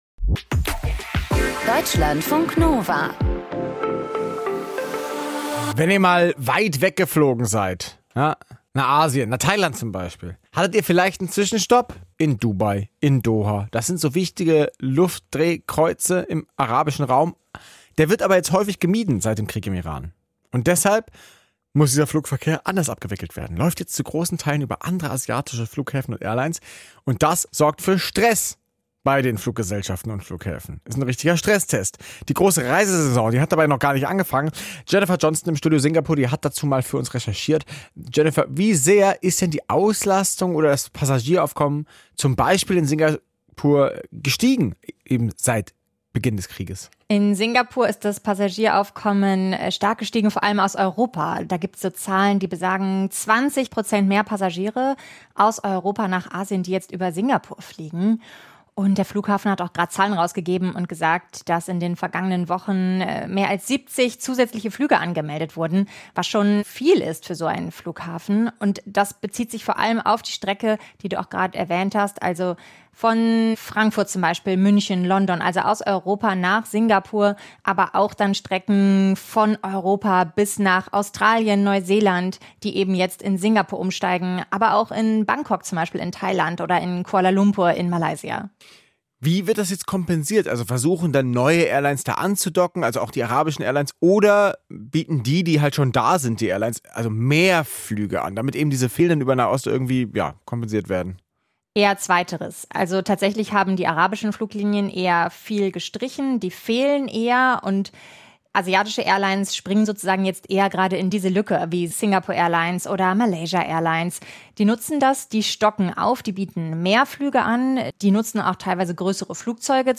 Moderation:
in Singapur